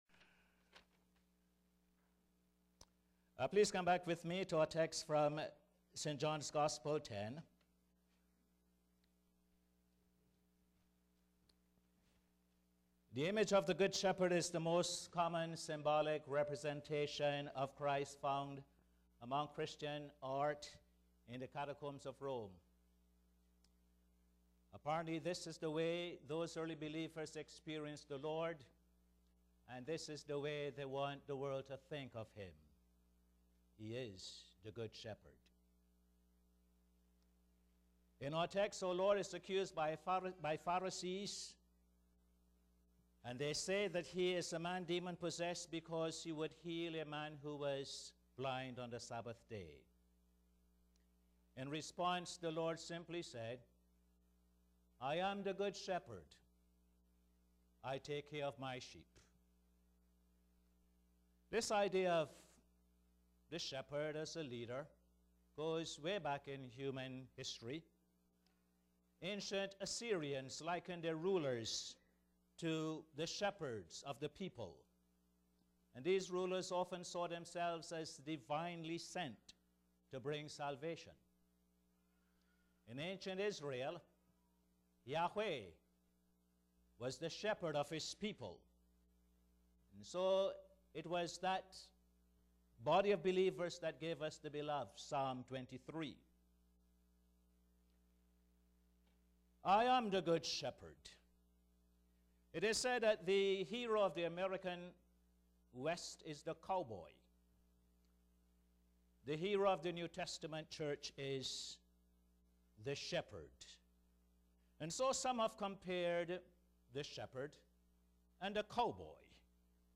Posted in Sermons on 08.